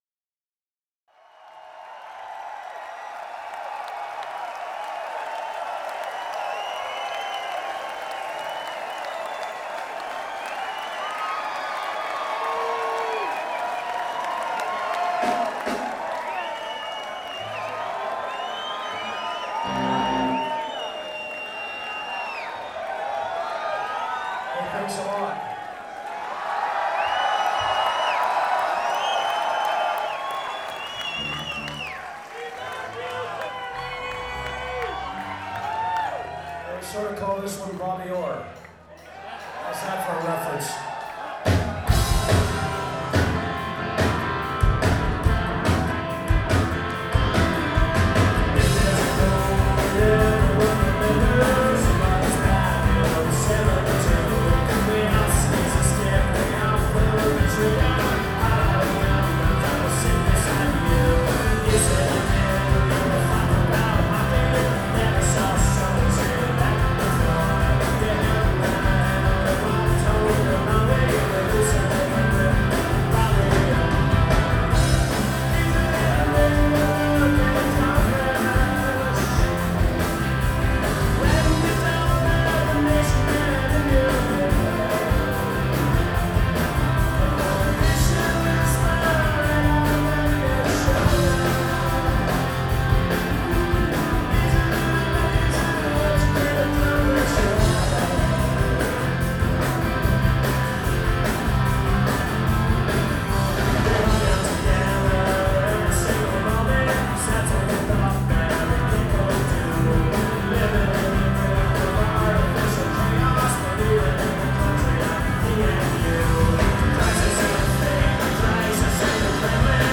Live In 1997